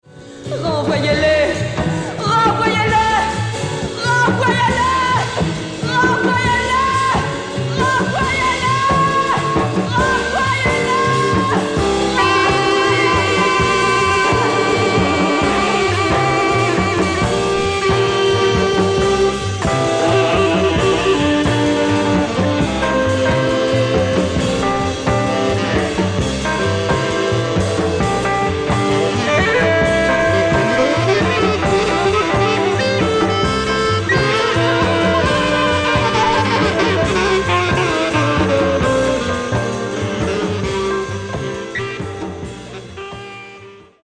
フリー/レアグルーヴ/フレンチ・ジャズ
抑制の効いたリズムに乗せ、ジワジワと高揚して行く様が圧巻の名曲です。